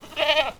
goat_call_06.wav